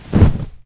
clothing_drop.WAV